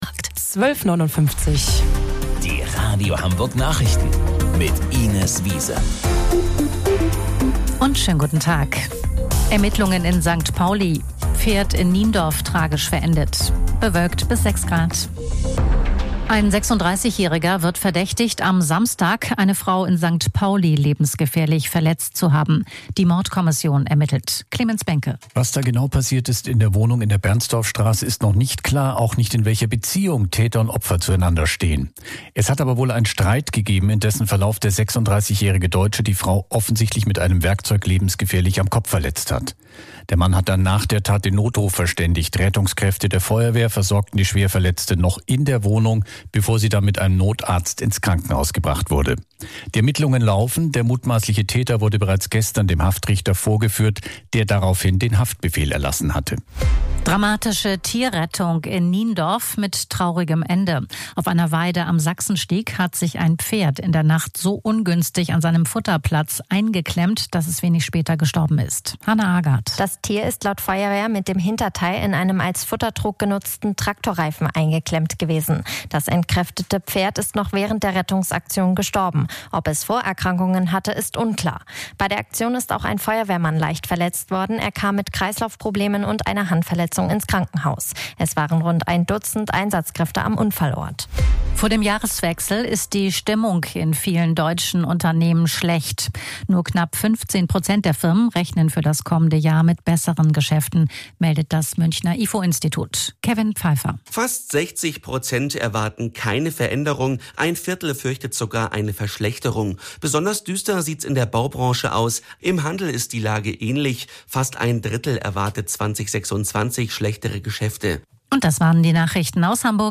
Radio Hamburg Nachrichten vom 22.12.2025 um 13 Uhr